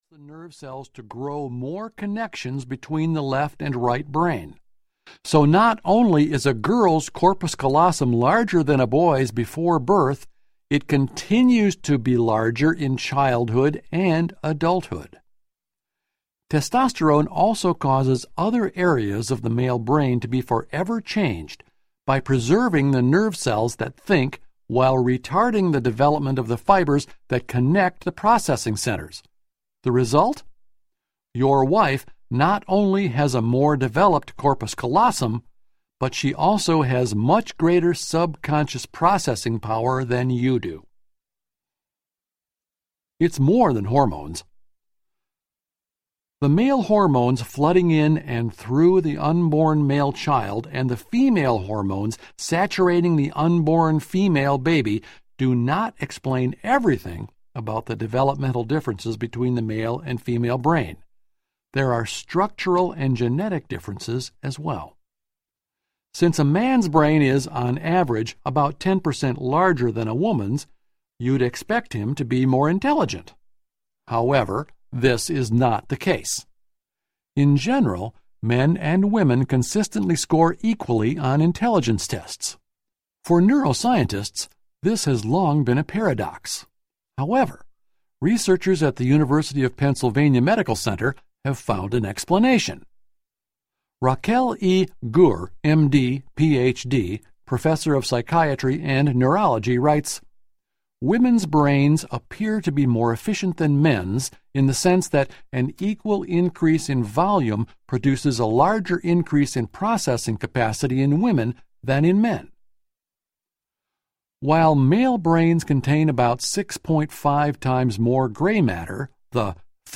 His Brain, Her Brain Audiobook
6 Hrs. – Unabridged